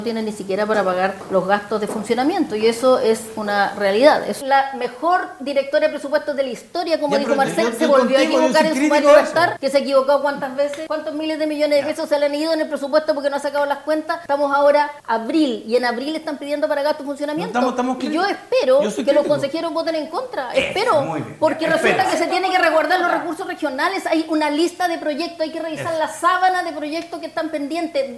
En el último programa de El Tablón de Canal 9, los parlamentarios de derecha Flor Weisse y Leónidas Romero, en conjunto con el pre candidato a diputado Alejandro Navarro y la seremi de Gobierno Jacqueline Cárdenas, abordaron la solicitud de dineros de la Subsecretaría de Redes Asistenciales a los GORE.